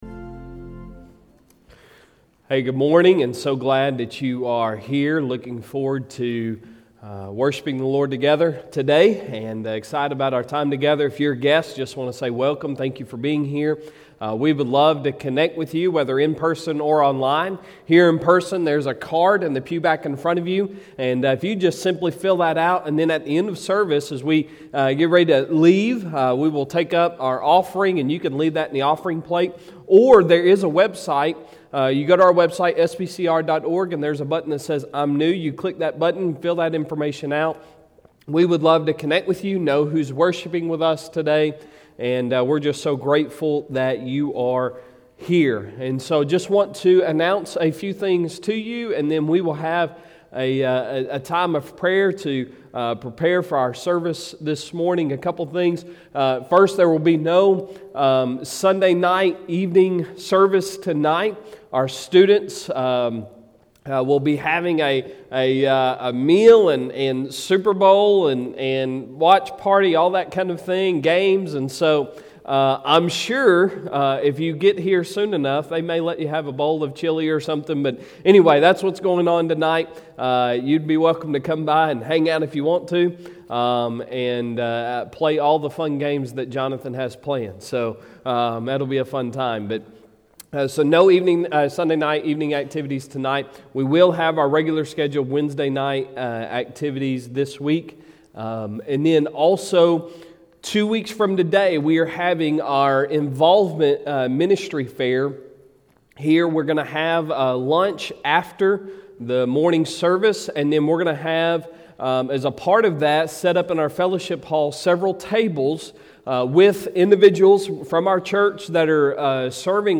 Sunday Sermon February 13, 2022